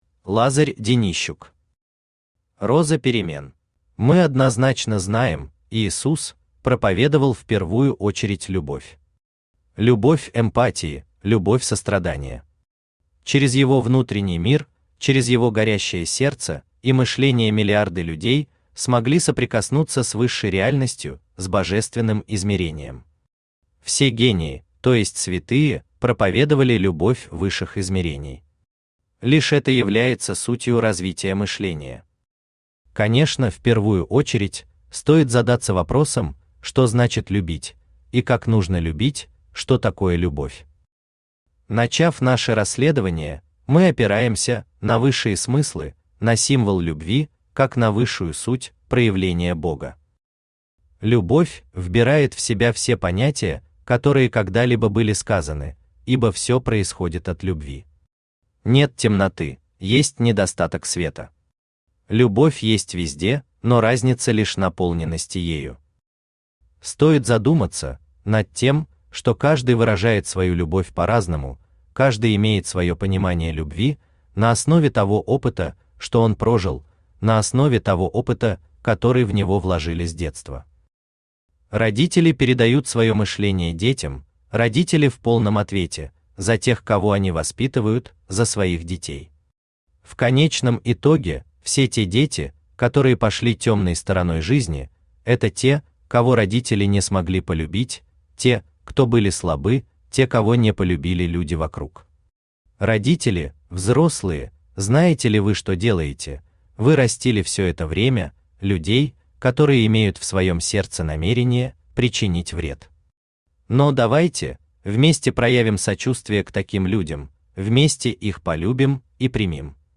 Читает: Авточтец ЛитРес
Аудиокнига «Роза перемен».